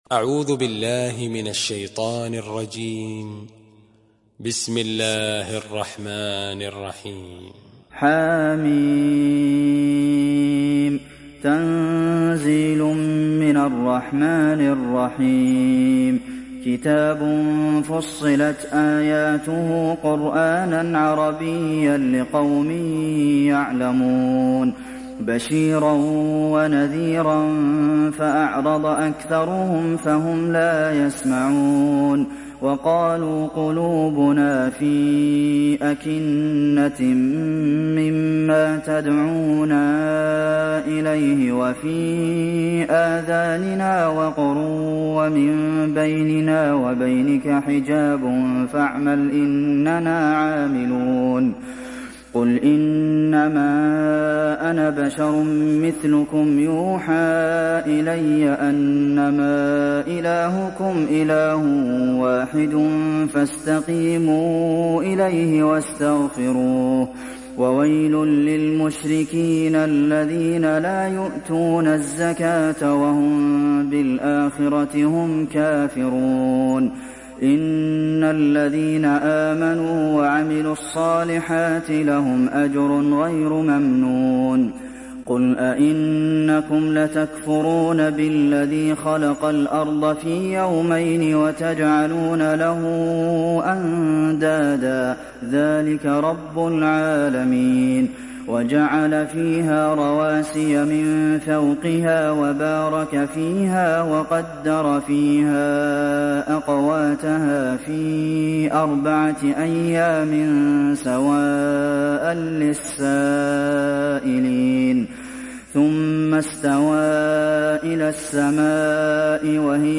دانلود سوره فصلت mp3 عبد المحسن القاسم (روایت حفص)